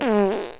fart.ogg